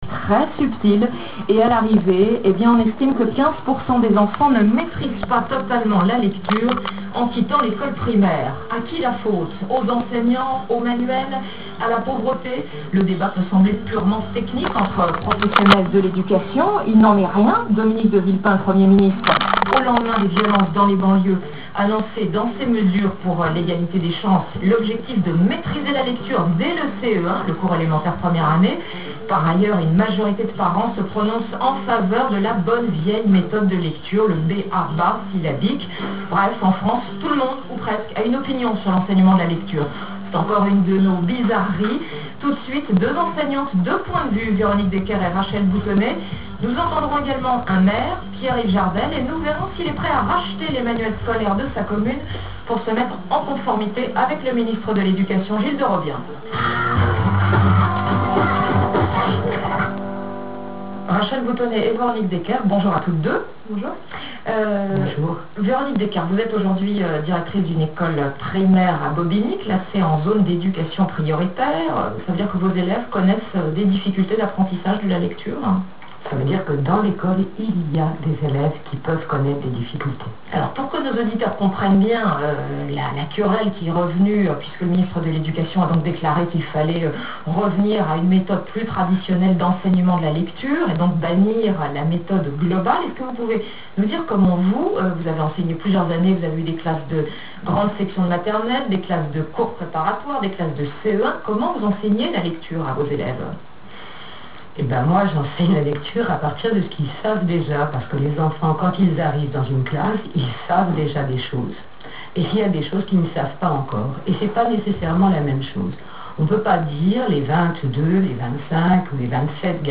d�bat � la radio
enregistr� et diffus� sur RFI en janvier 2006 - sur le th�me de la lecture